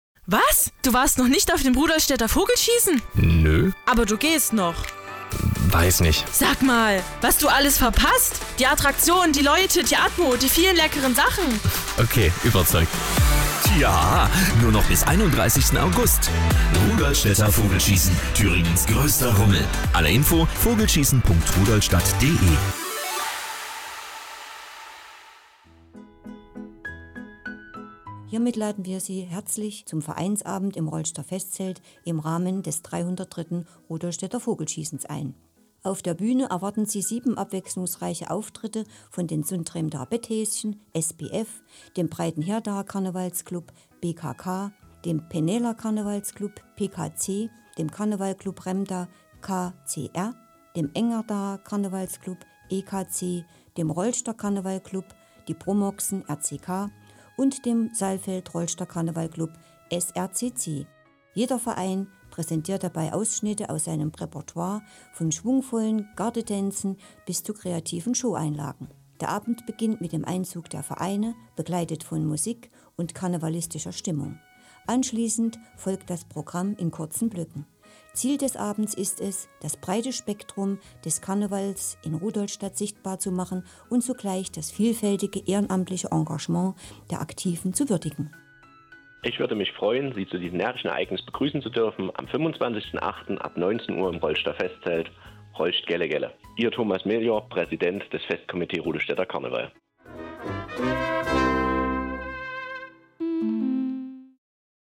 Magazinbeitrag/ Interview im Radio SRB.